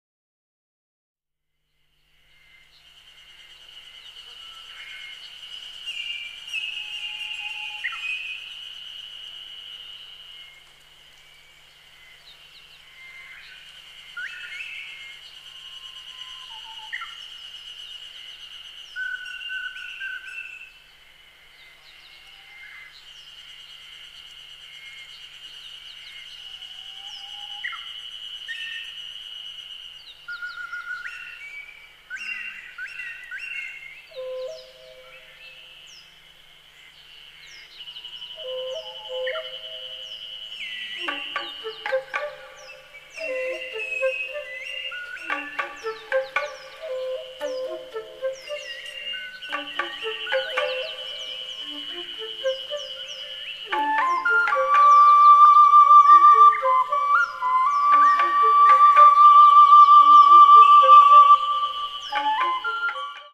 at studio Voice
岩木山麓、小鳥達との会話
口笛(自作陶器)
篠竹リコーダー
フルート
バス・フルート
竹琴(自作楽器)
アンクロン(インドネシア)